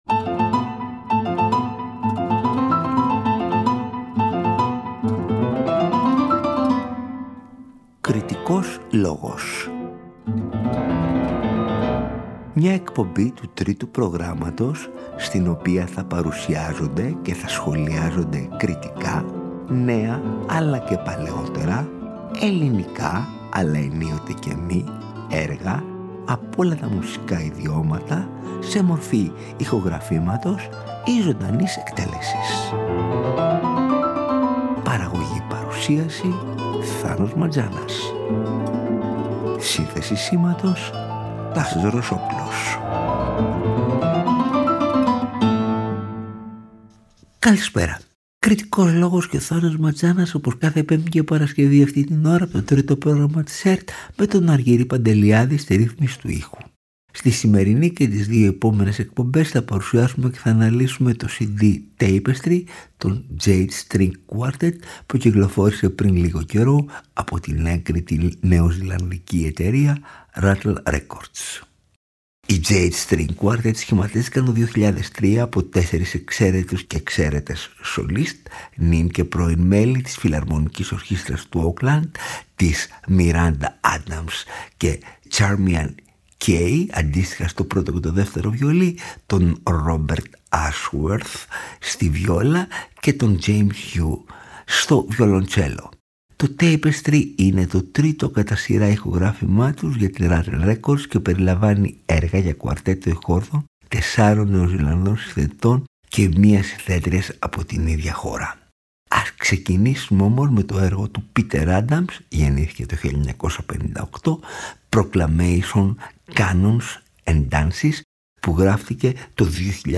Οι νέες γραφές για κουαρτέτο εγχόρδων